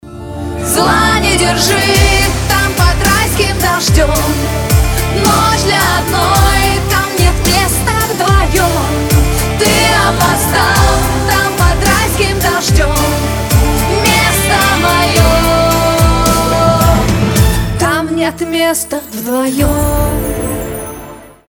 поп
женский вокал
дуэт